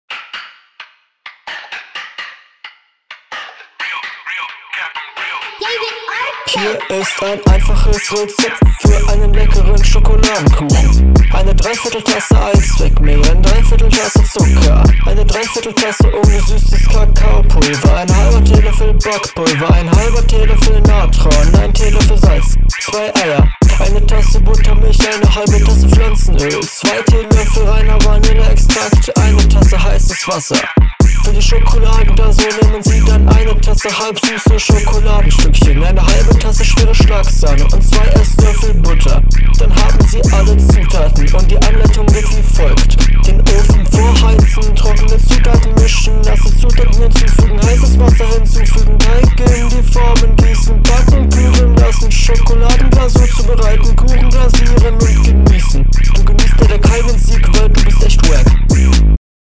witzige Idee nen Rezept vorzurappen … aber hat find ich wenig mit Battlerap zu tun …